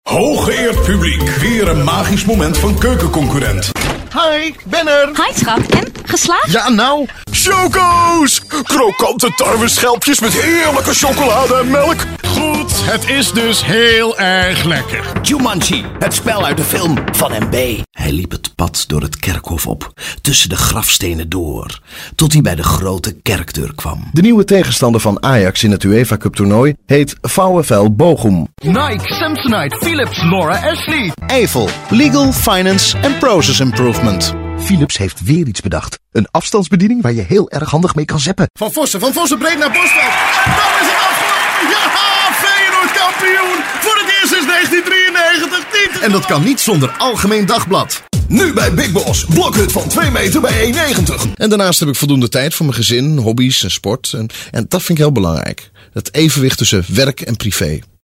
My voice is frequently asked for business, hardselling, friendly and comical tone of voices.
My categories: Mid to low range, powerful, Authoritative, Bass-Baritone, Big, Deep Dynamic, Versatile, Dramatic, Contemporary, Intense, Raw, Flat, Monotone, Subdued, Real, Natural, Hard-Sell, Professional, Corporate, Conversational, Guy-Next-Door Bright, Warm, Clean, Crisp, Clear, Energetic, Fresh, Comedy, Cute, Goofy, Character, Wacky, Cartoon, Maternal, Sarcastic, Genuine, Friendly, Fun, Confident, Enthusiastic, Easy Going, Pleasant, Friendly, Sincere, Knowledgeable, Compassionate, Believable, Intimate, Playful, Informative
Sprecher niederländisch, holländisch für Werbung, Imagefilme, Zeichentrick, DVD-Spiele etc.
Sprechprobe: Werbung (Muttersprache):